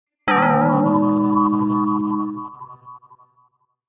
Мультяшный звук удара по голове с кружащейся головой